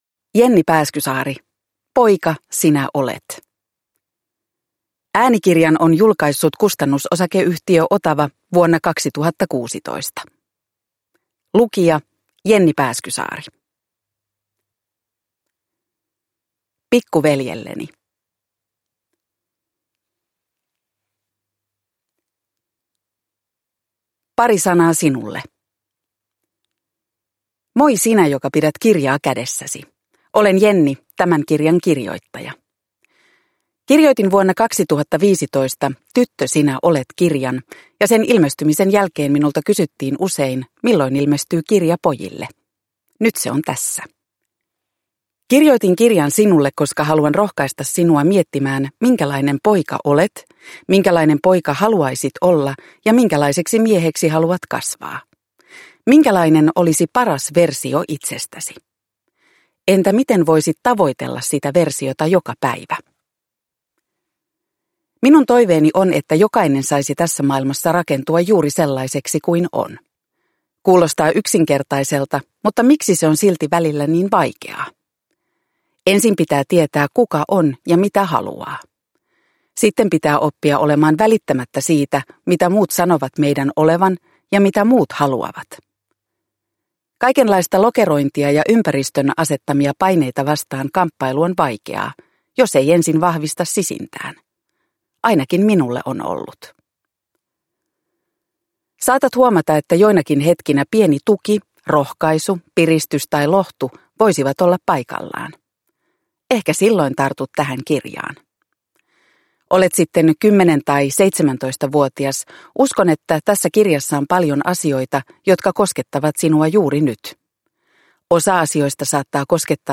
Poika, sinä olet... – Ljudbok – Laddas ner